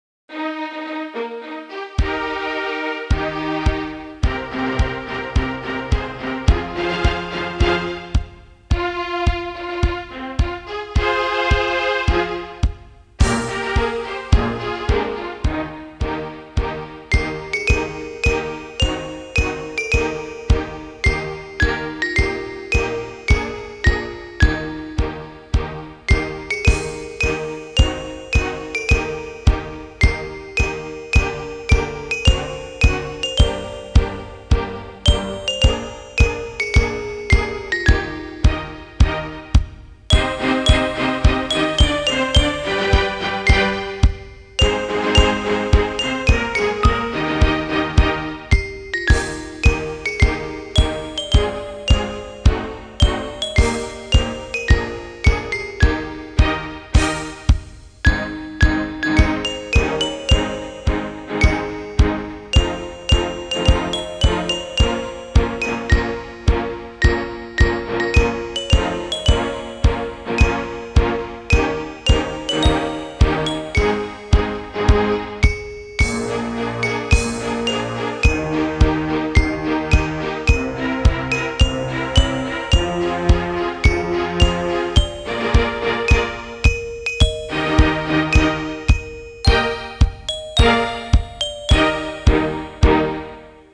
HYMN.WAV